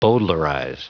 Prononciation du mot bowdlerize en anglais (fichier audio)
Prononciation du mot : bowdlerize